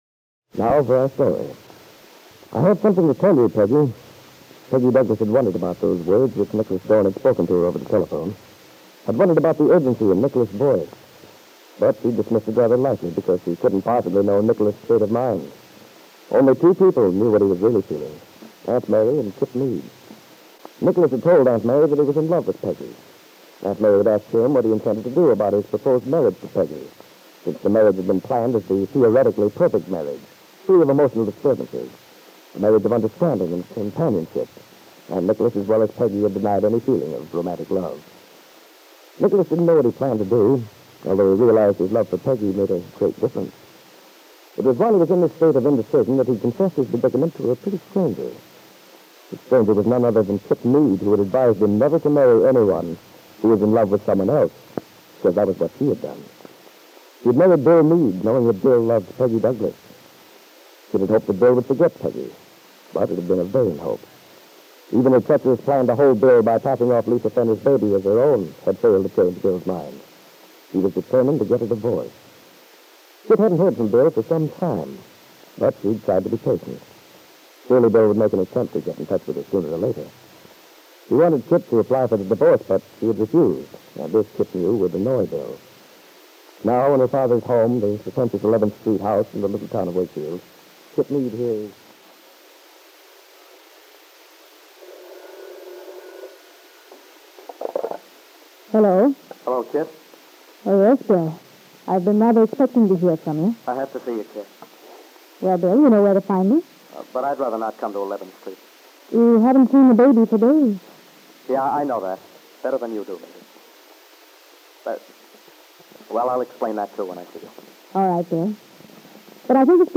Aunt Mary was a soap opera that follows a story line which appears to have been broadcast in 1945 and early 1946. The plot features a love triangle and involves a young woman in a failing marriage who pays an extended visit to "friends" in Los Angeles to conceal a dark secret from her father and others back in Wakefield.